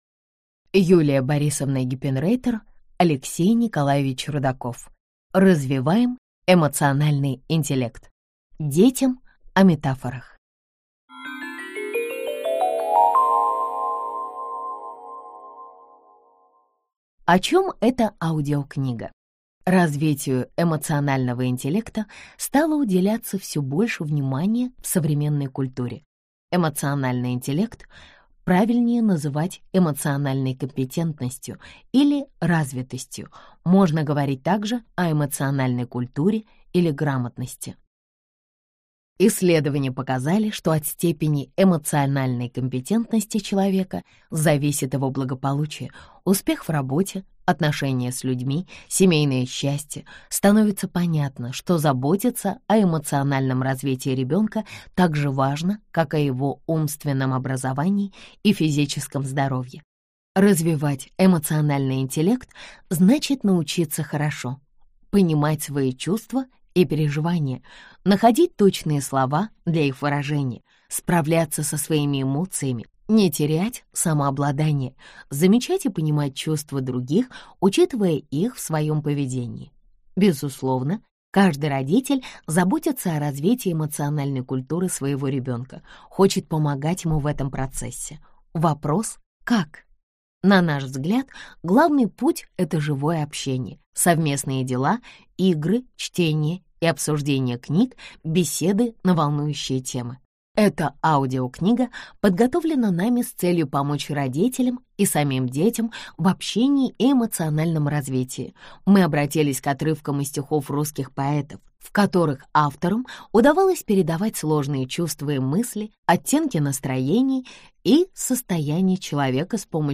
Аудиокнига Развиваем эмоциональный интеллект. Детям о метафорах | Библиотека аудиокниг